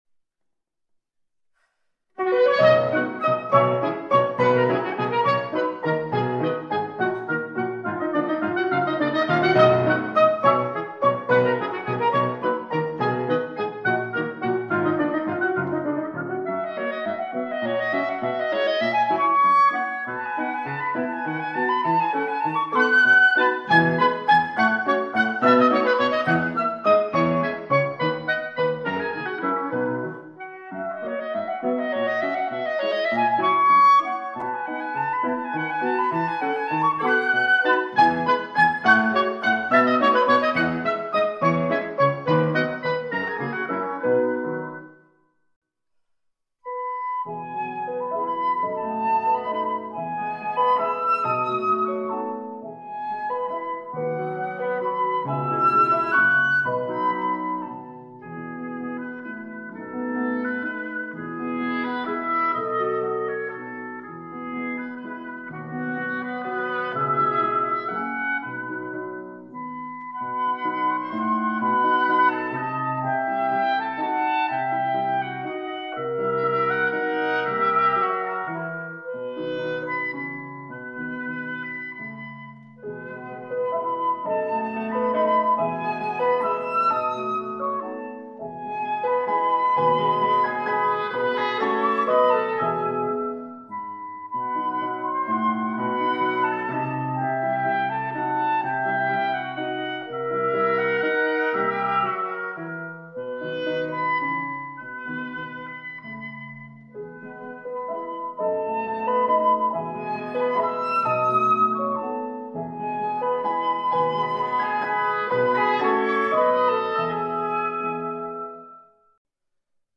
Flauta, oboe, clarinete y piano
Flauta, oboe, clarinete, fagot y piano
Flauta, oboe, clarinete, trompa, fagot y piano